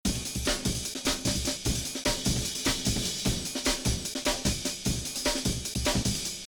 Использую супериор плюс встроенные обработки аблетона.